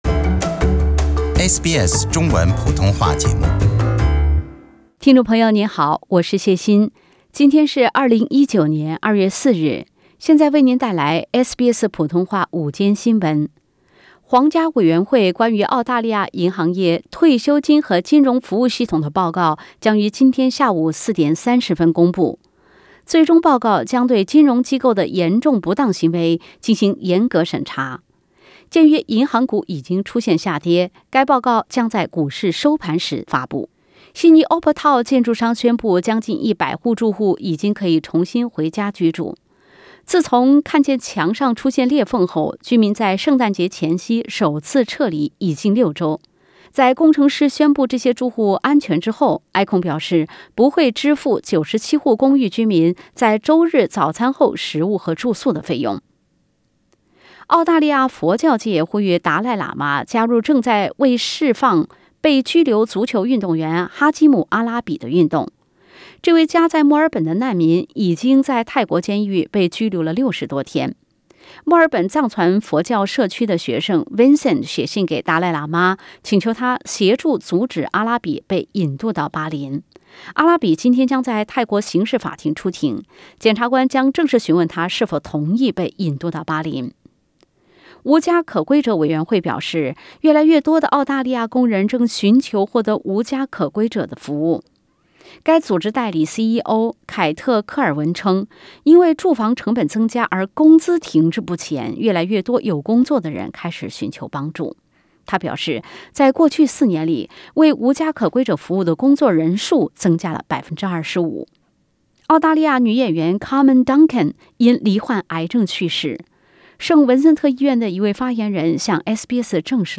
SBS Midday News Source: Pixabay